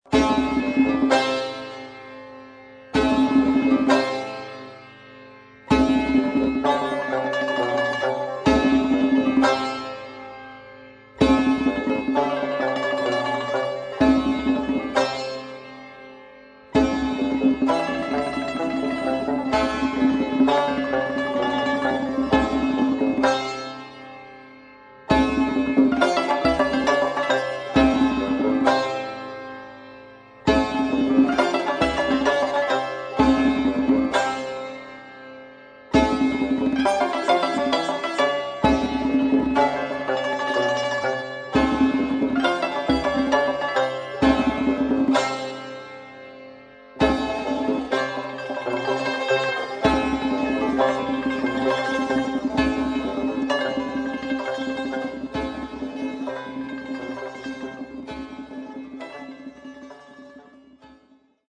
در دستگاه چهار گاه